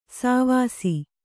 ♪ sāvāsi